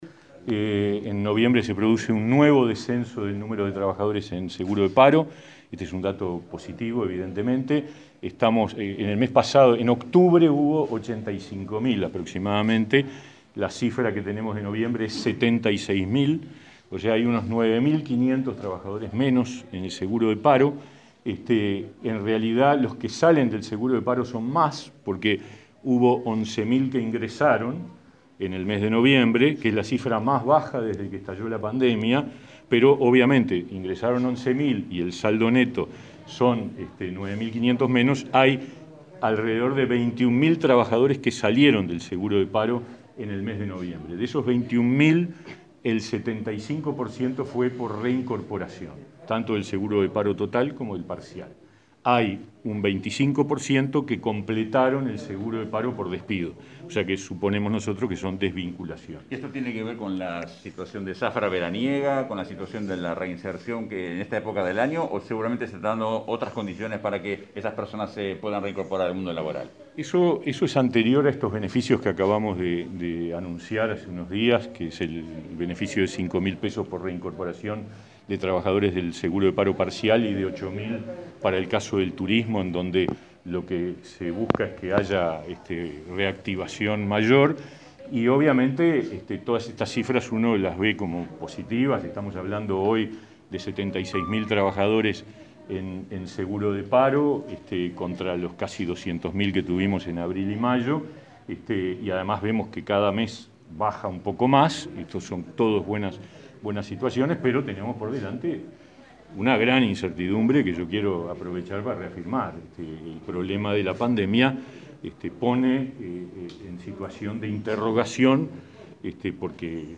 En noviembre hubo 9.500 trabajadores menos en seguro de desempleo que en el mes anterior, informó este viernes 11 el ministro de Trabajo y Seguridad Social, Pablo Mieres, en conferencia de prensa. Indicó que ingresaron 11.000 trabajadores, lo que representa la cifra más baja desde marzo, y egresaron 21.000, de los cuales el 75% correspondieron a reincorporaciones totales o parciales y el 25% completaron el seguro.